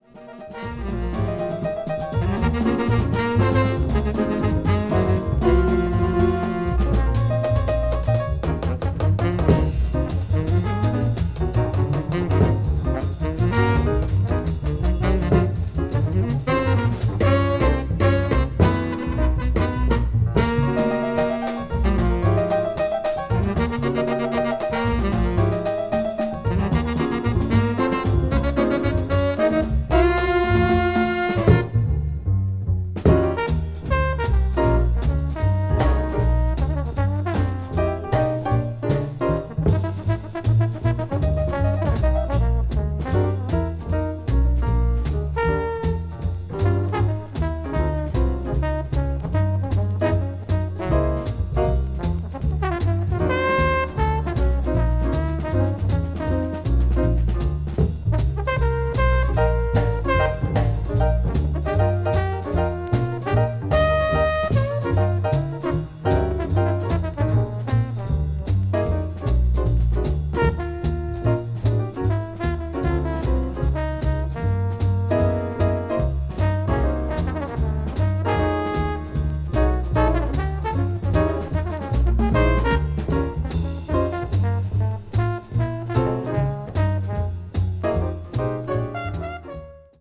hard bop   modern jazz   west coast jazz